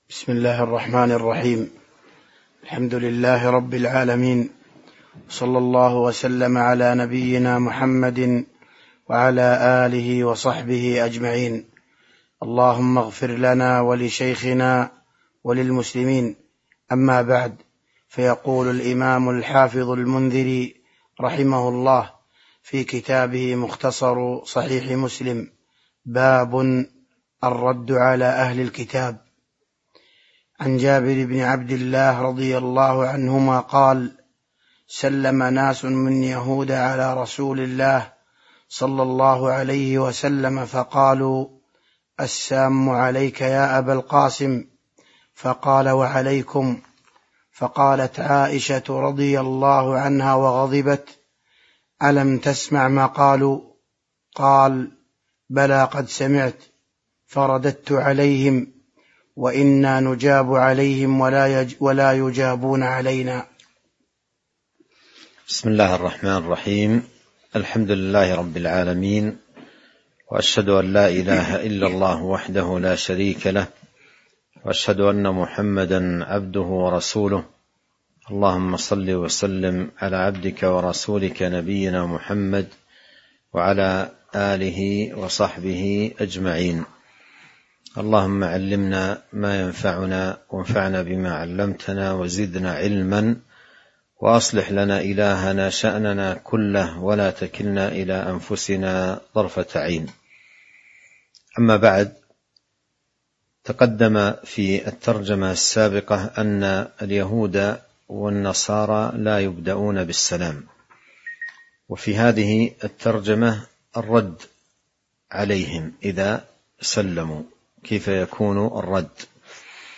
تاريخ النشر ١٤ رجب ١٤٤٣ هـ المكان: المسجد النبوي الشيخ